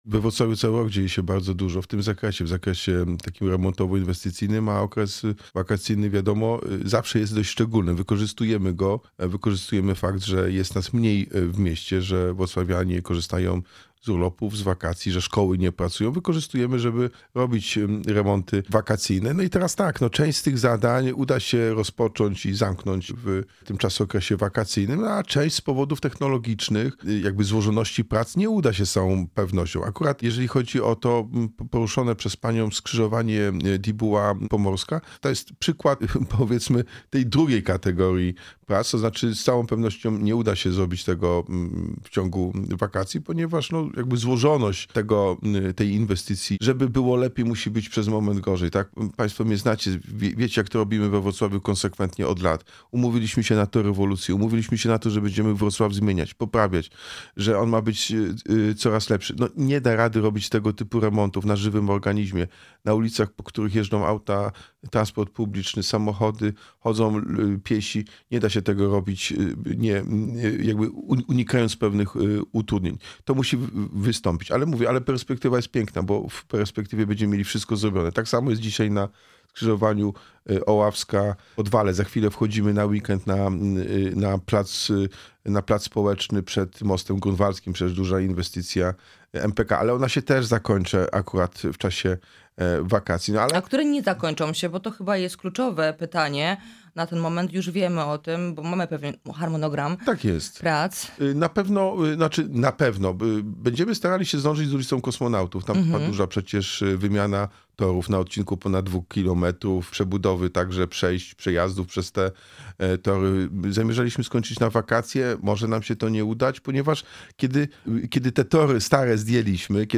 Jacek Sutryk – prezydent Wrocławia był naszym gościem.